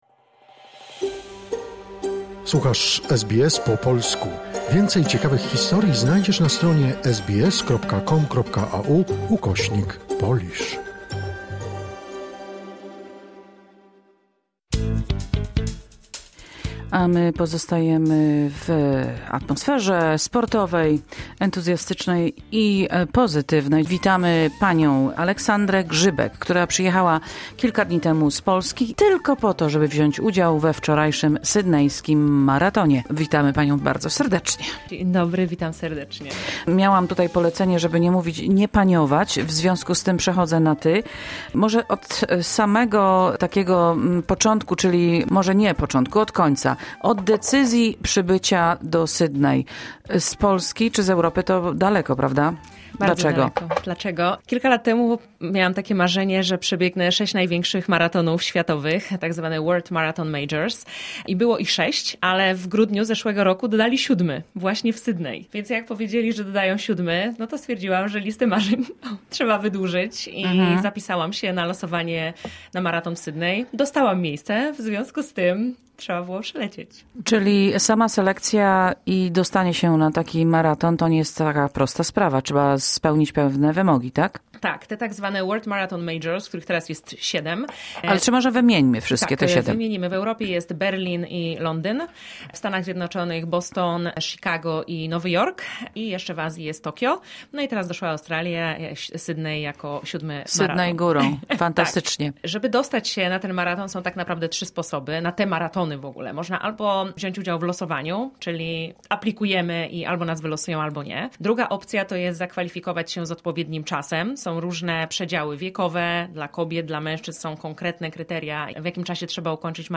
w studio SBS